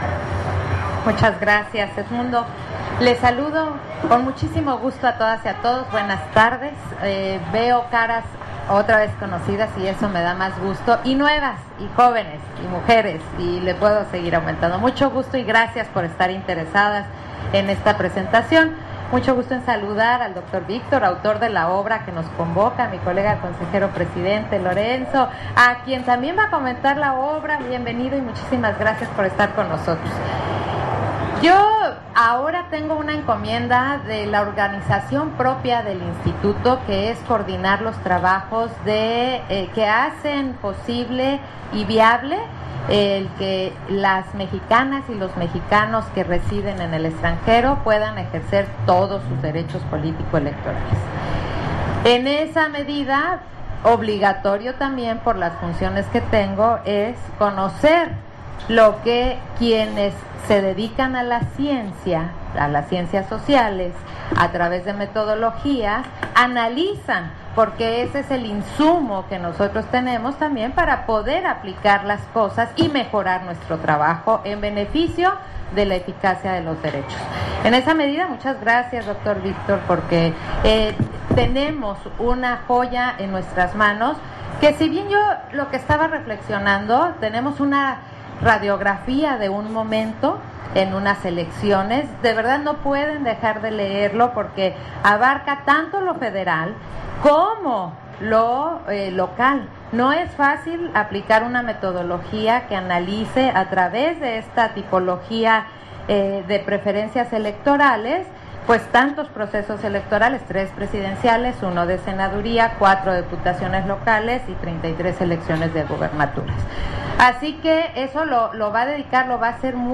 Intervención de Claudia Zavala, en la presentación del libro, El sufragio extraterritorial de las y los mexicanos. Participación, preferencias políticas y tipología del voto a distancia